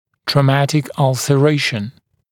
[trɔː’mætɪk ˌʌlsə’reɪʃn][тро:’мэтик ˌалсэ’рэйшн]травматическое изъязвление